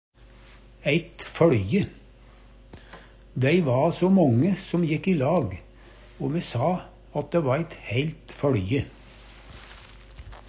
eit føLje - Numedalsmål (en-US)